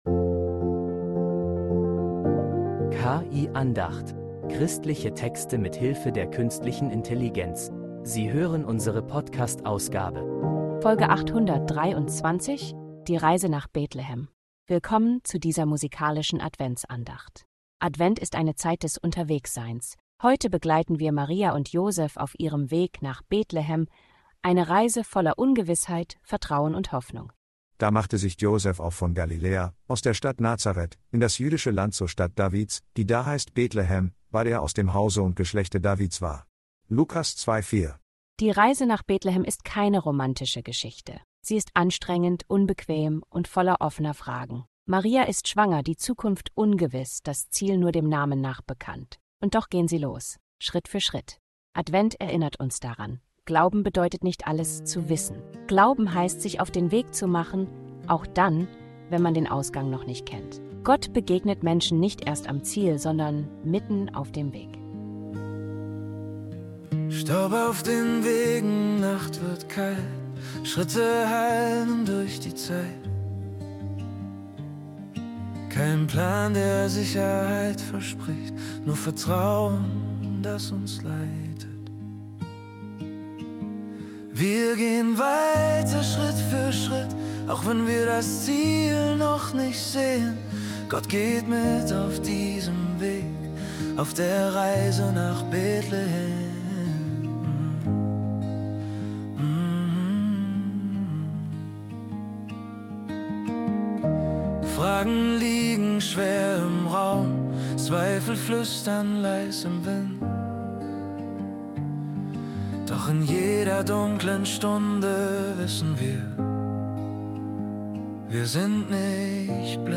Ein ruhiges Lied begleitet